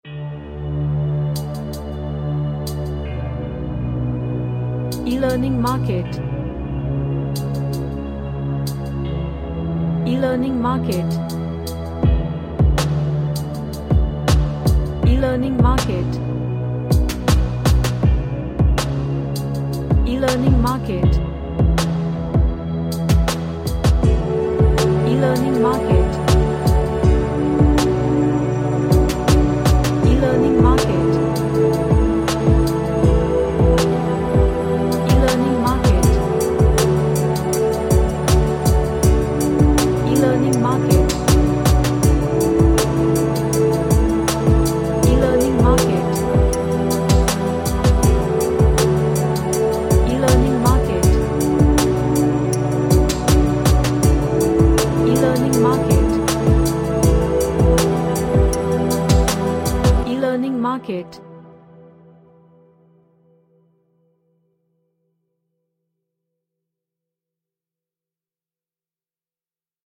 A Epic Pads track.
Relaxation / Meditation